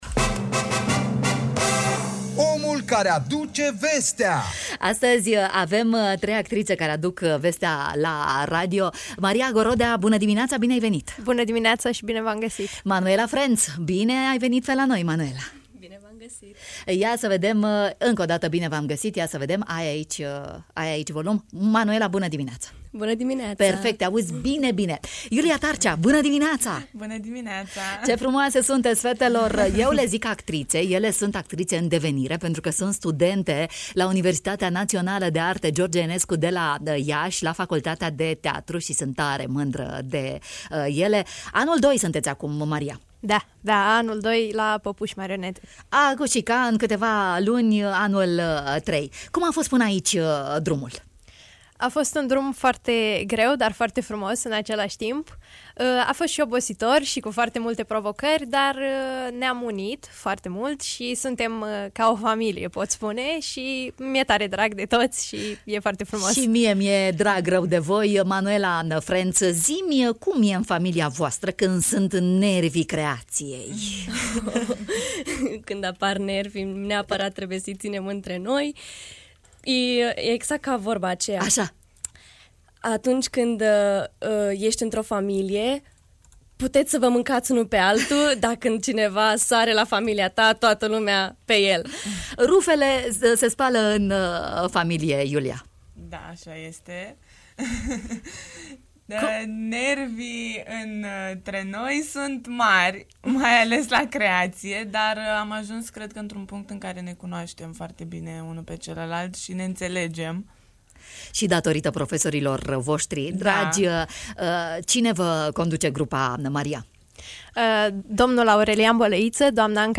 în direct la matinal